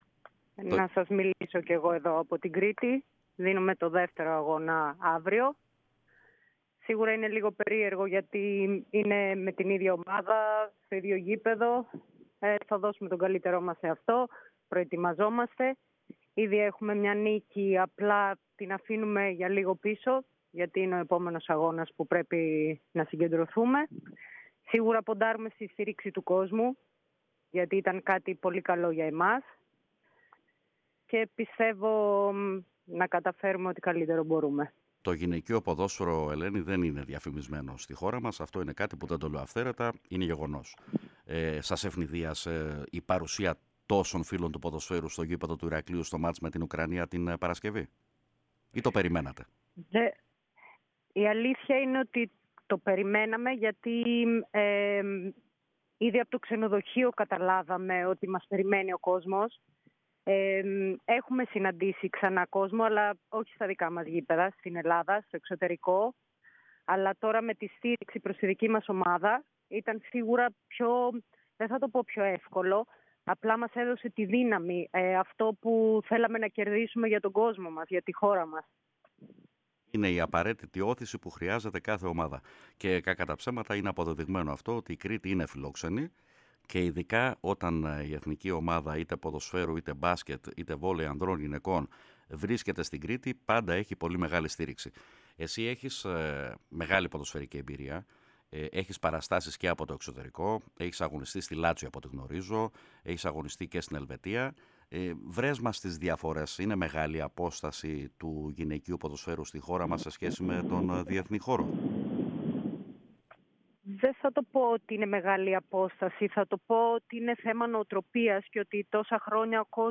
H Eλληνίδα ποδοσφαιρίστρια μίλησε στον αέρα της ΕΡΑ ΣΠΟΡ και την εκπομπή “Μπαλαντέρ”